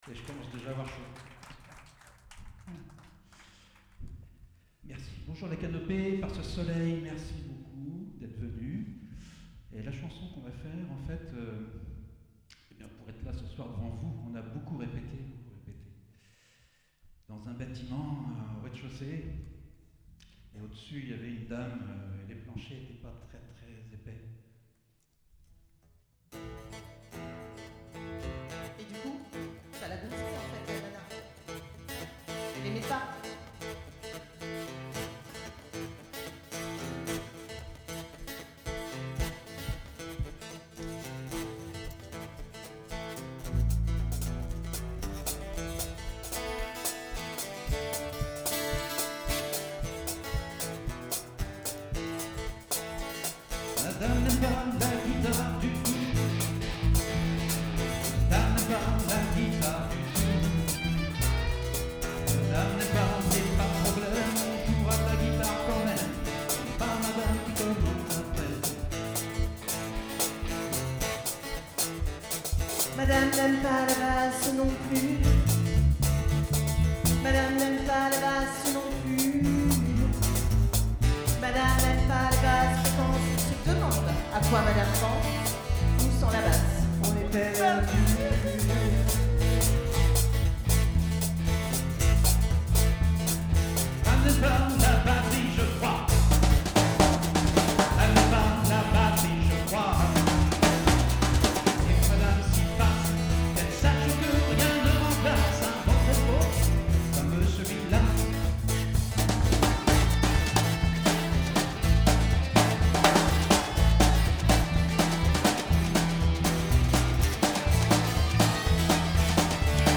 Chant
Batterie
Guitare électrique
Guitare basse
Guitare acoustique et chant
⇓ Extrait audio (concert) ⇓